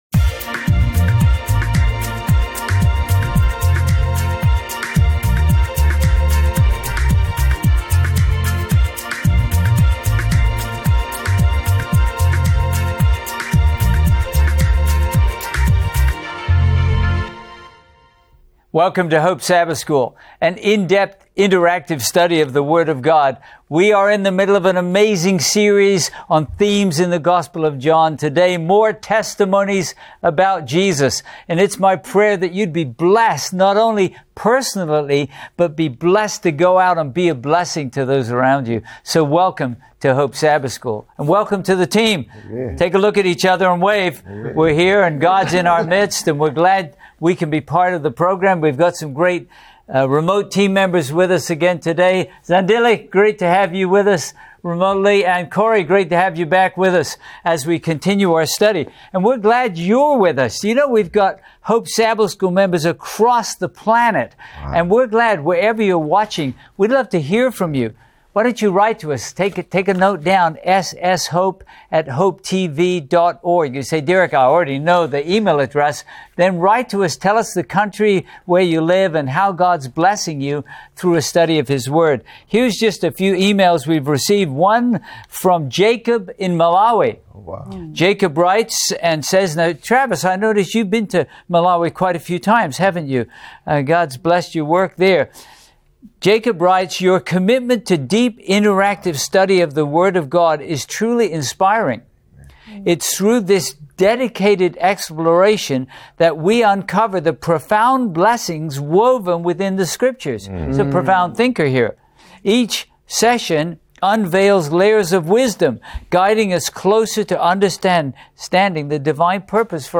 This lesson will focus on reactions from John the Baptist, the crowd of 5,000, and Jesus himself.